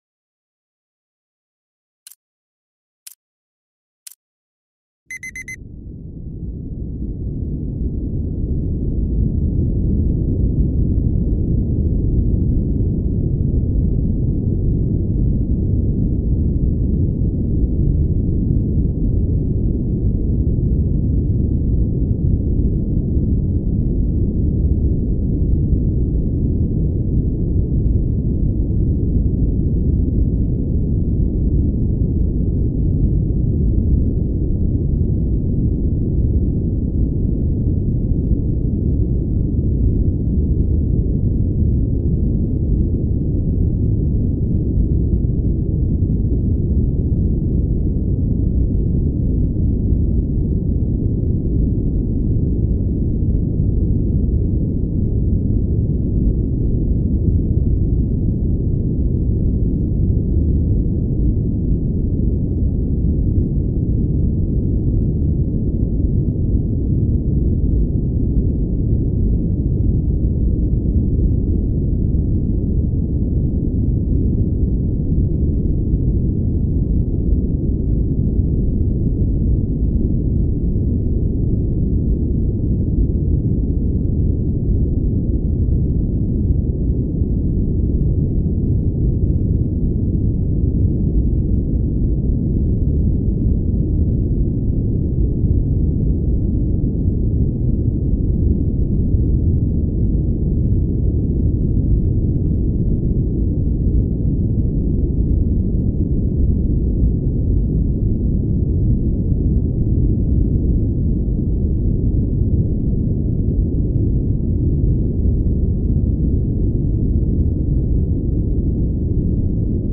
【集中力向上】ブラウンノイズ×ポモドーロタイマー 1時間